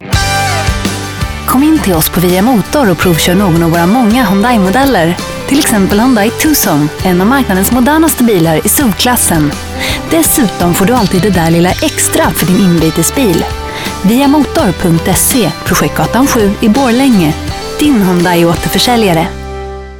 Commercial 4